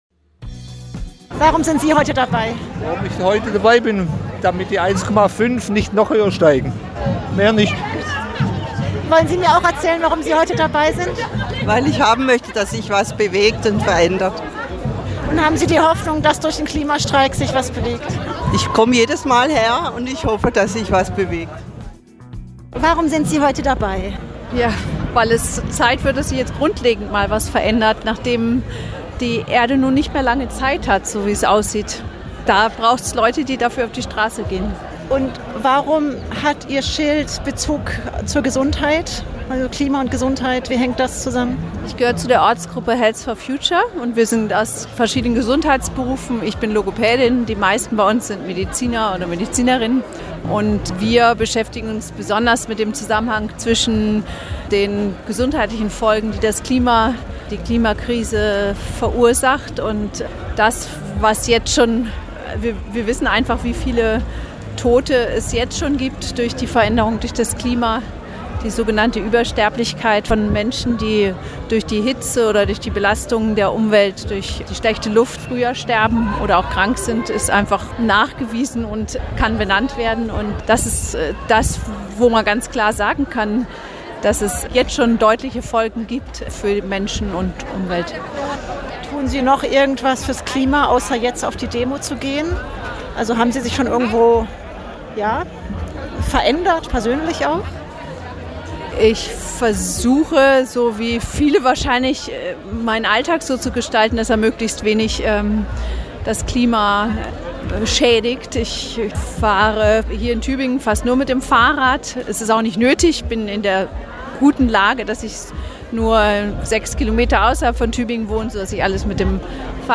Hier hört Ihr Interviews mit Redner*innen, mit Streikenden und die Abschlusskundgebung von Fridays for Future.
Straßeninterviews
97124_Klimastreik-Strasseninterviews.mp3